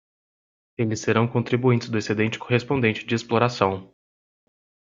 Pronounced as (IPA)
/e.seˈdẽ.t͡ʃi/